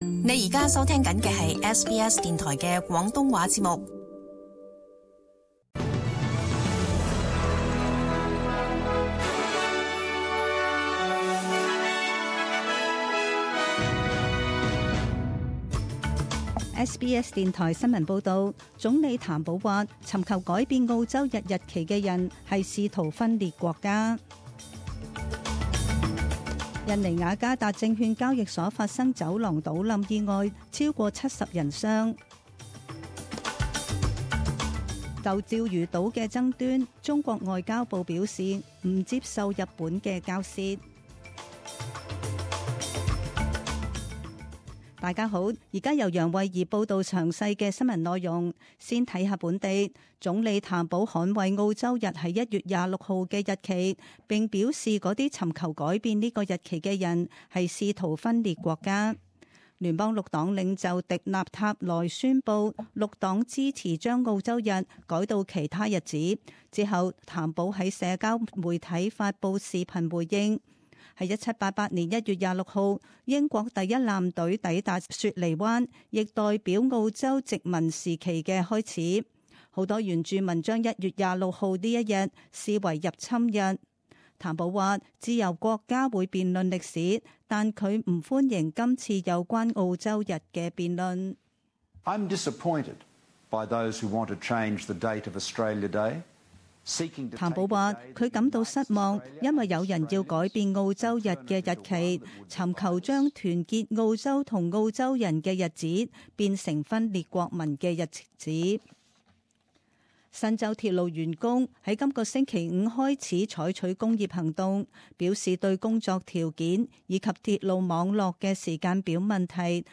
SBS Cantonese 10am news Source: SBS